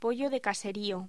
Locución: Pollo de caserío
voz